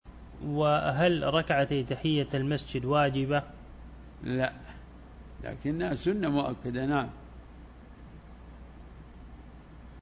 فتاوى الدروس